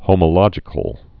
(hōmə-lŏjĭ-kəl, hŏmə-) also ho·mo·log·ic (-lŏjĭk)